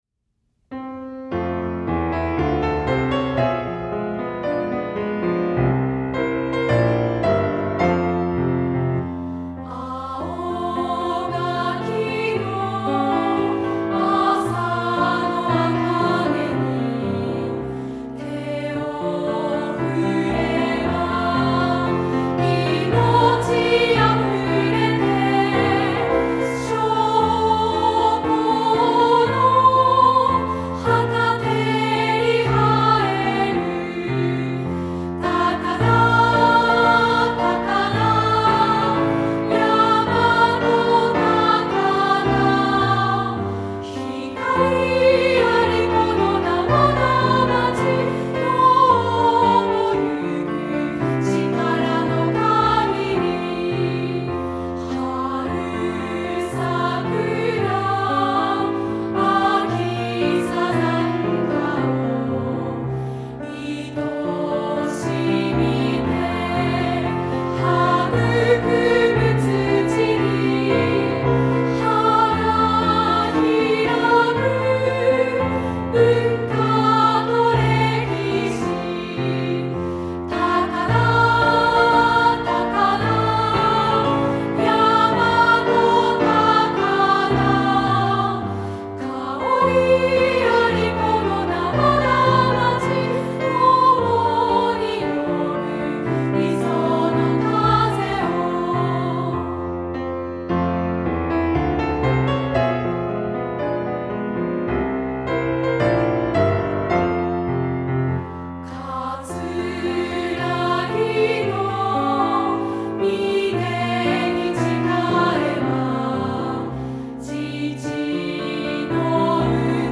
フルコーラス